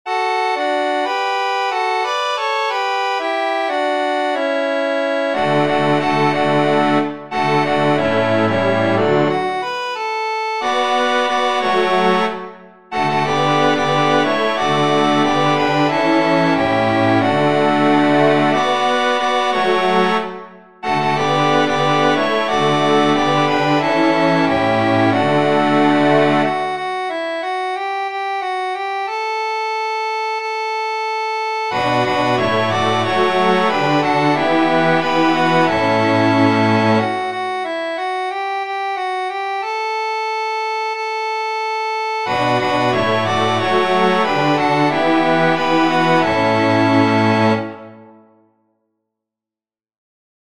Antienne d'ouverture Téléchargé par